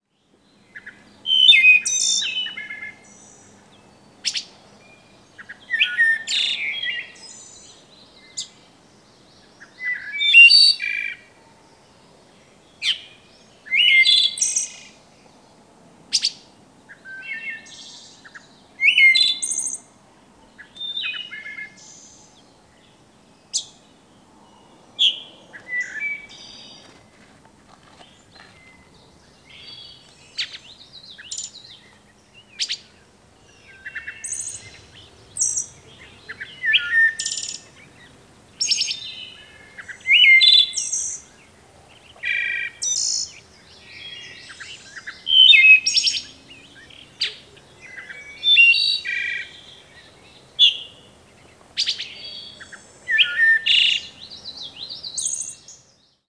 Wood Thrush diurnal flight calls
Diurnal calling sequences:
Two flight calls are interspersed with song from a perched bird on the breeding grounds.